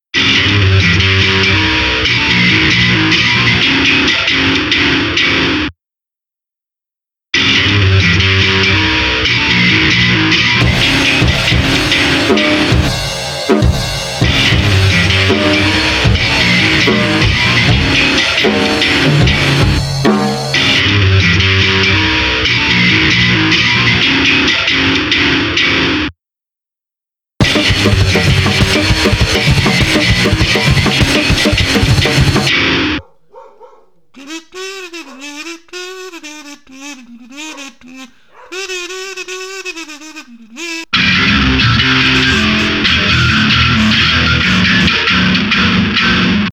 punk instrumental 2025